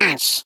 Sfx_tool_spypenguin_vo_hit_wall_11.ogg